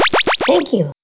voice_thankyou.wav